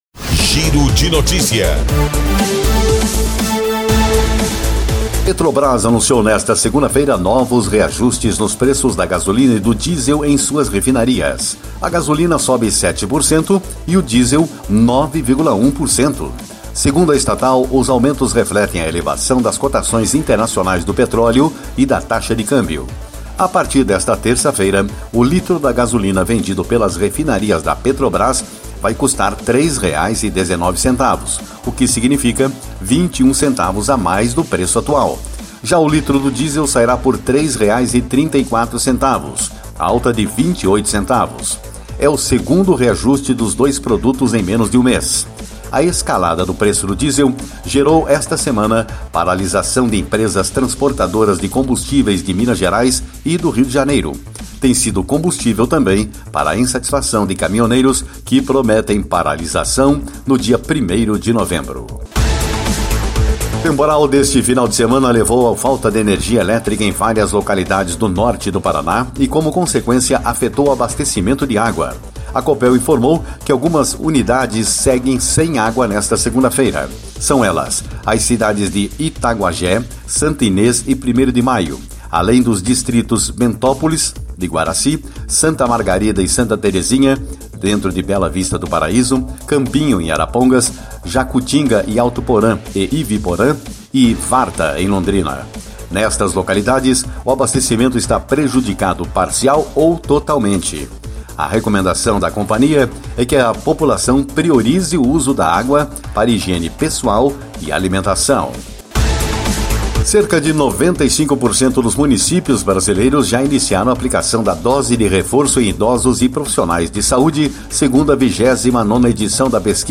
Giro de Notícias Tarde